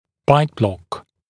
[baɪt blɔk][байт блок]окклюзионная накладка